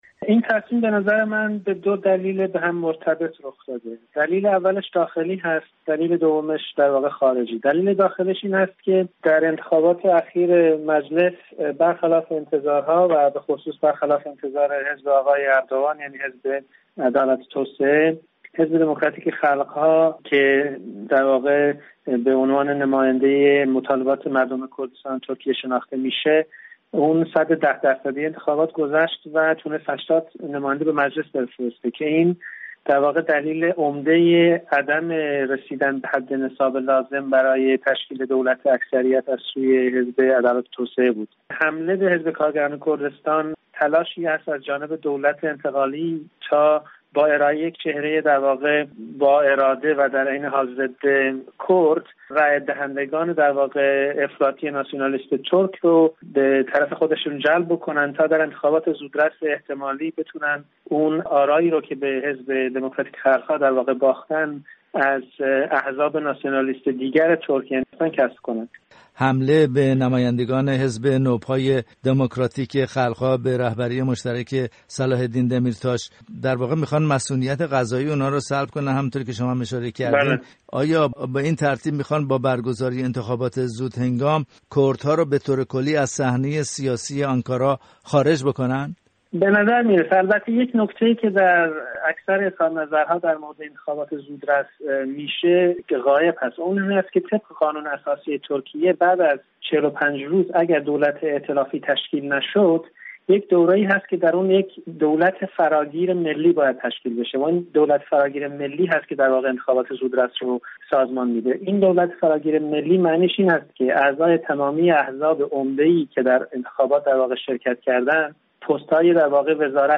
گفت‌وگوی